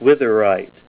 Help on Name Pronunciation: Name Pronunciation: Witherite + Pronunciation
Say WITHERITE Help on Synonym: Synonym: ICSD 15196   PDF 45-1471